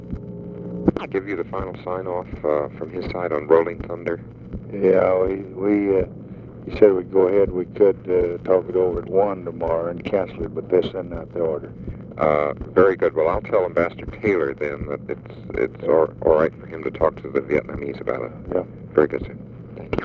Conversation with (possibly) BROMLEY SMITH, February 25, 1965
Secret White House Tapes